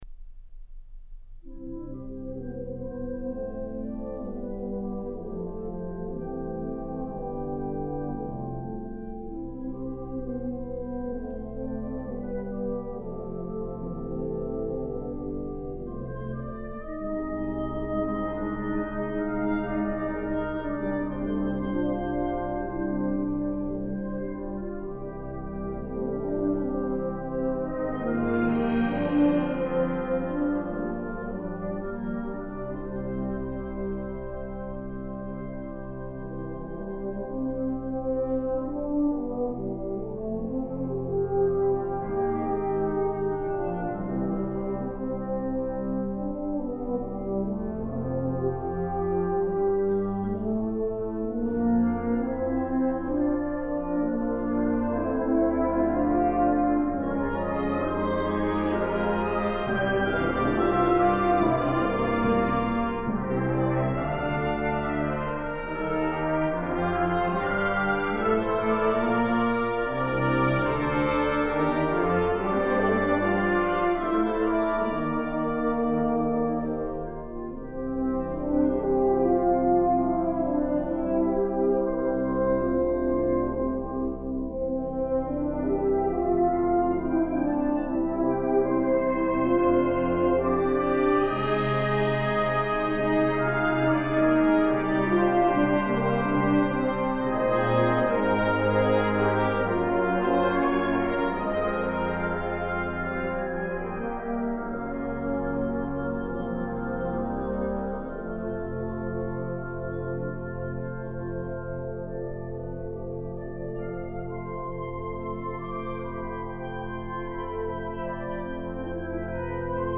Genre: Band
A beautiful and rich composition for the developing band.
Euphonium
Percussion 1 (vibraphone, bells, chimes)
Percussion 2 (suspended cymbal, snare drum, wind chimes)